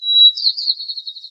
由JCG Musics于2015年创建的名为AmbiGen的装置中使用的单个鸟啁啾和短语。
Tag: 鸟鸣声 自然 现场录音